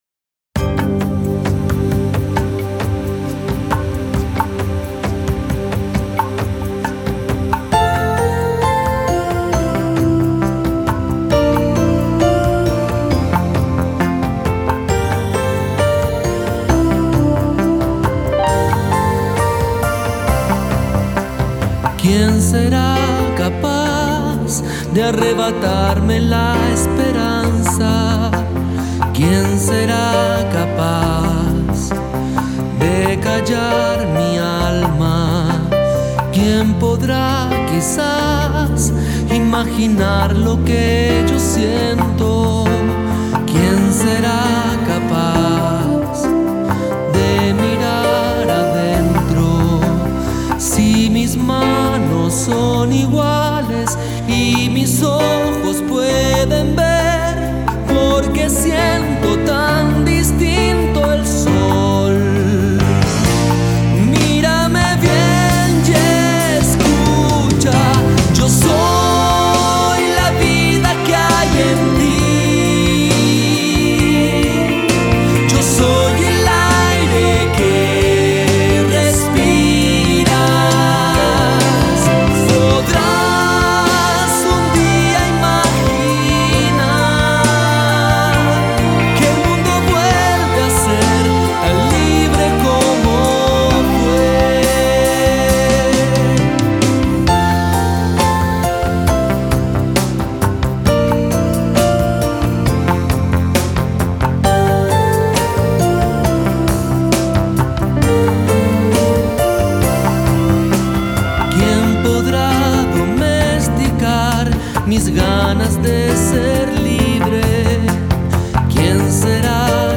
GenerePop / Musica Leggera